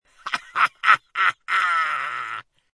Descarga de Sonidos mp3 Gratis: risa 8.